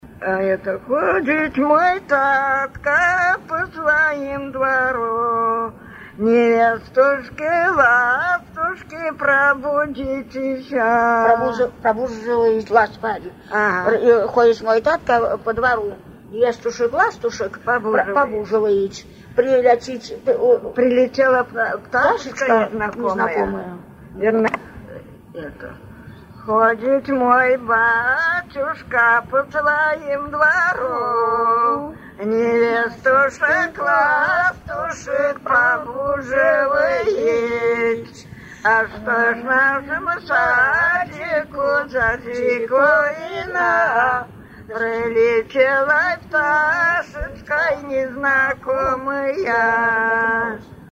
Свадебные обрядовые песни в традиции верховья Ловати